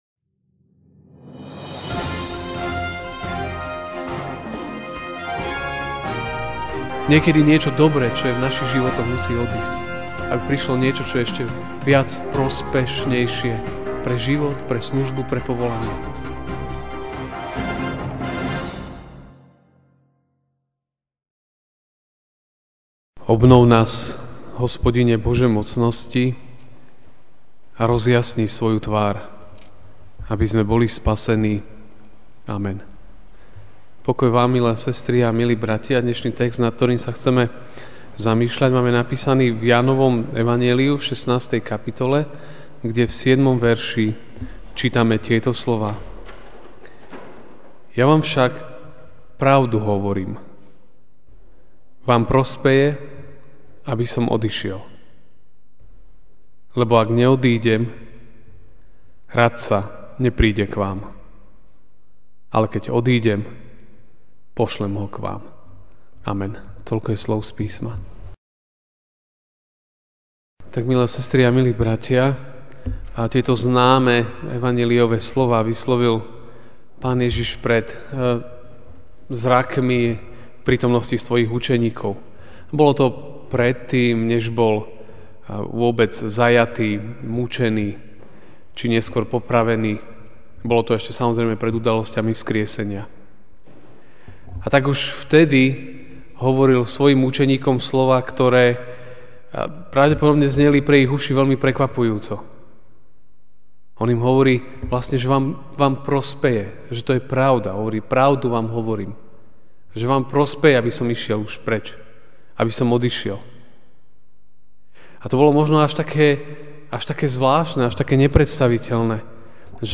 máj 25, 2017 Vám prospeje, aby som odišiel MP3 SUBSCRIBE on iTunes(Podcast) Notes Sermons in this Series Večerná kázeň Vám prospeje, aby som odišiel (Ján 16, 7) Ja vám však hovorím pravdu: Pre vás je lepšie, keď odídem.